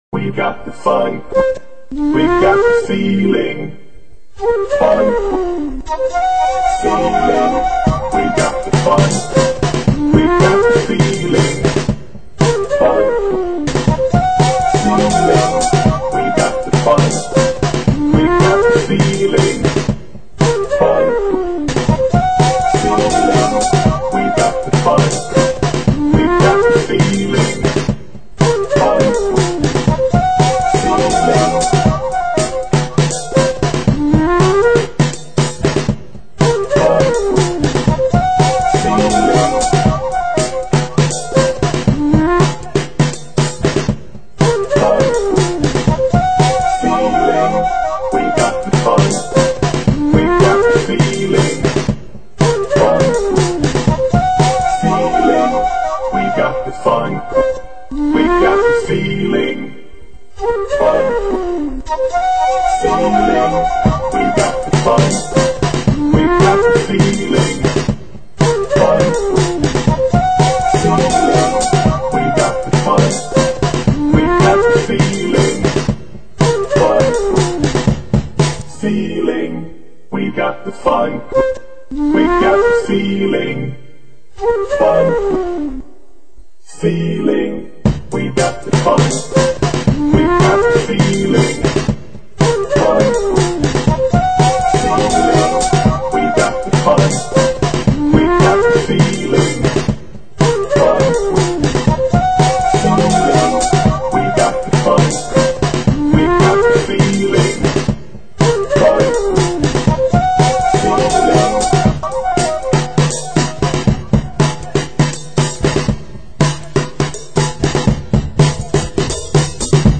-SweCosmicFunkFlow
vocals, keybord and programming.
vocals and brass.
percussion.